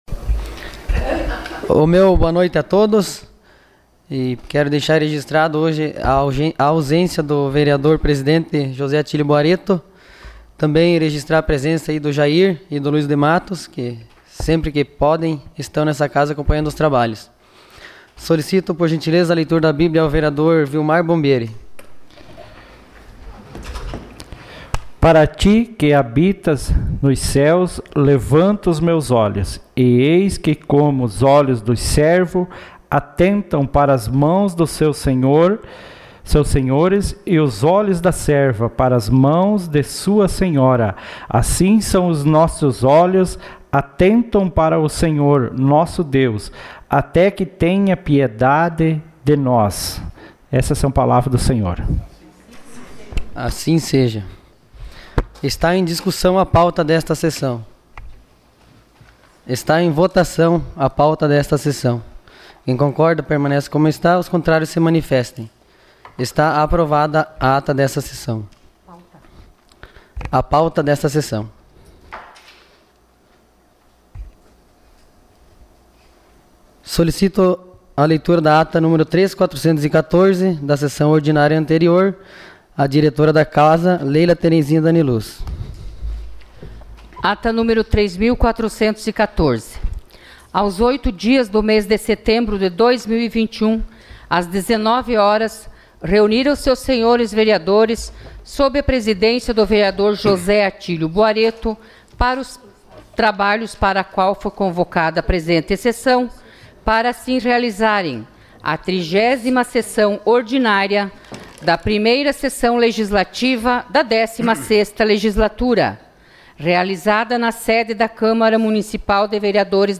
Sessão Ordinária dia 13 de setembro de 2021